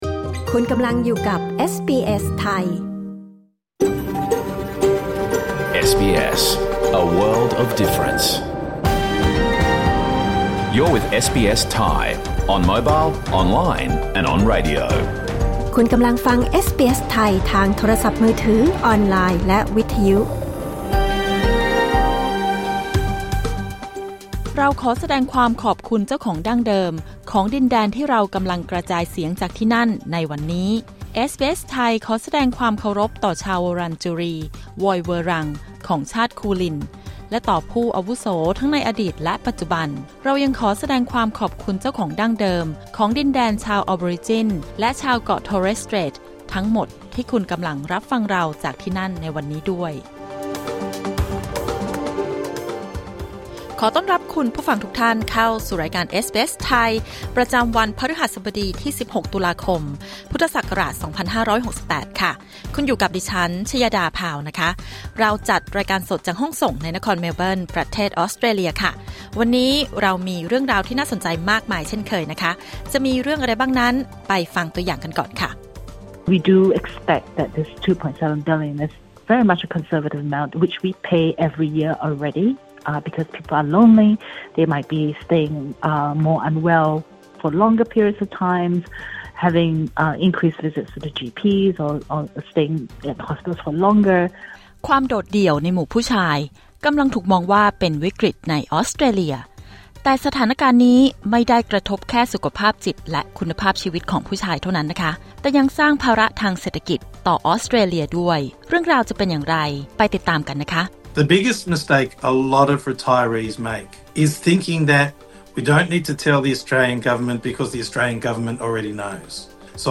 รายการสด 16 ตุลาคม 2568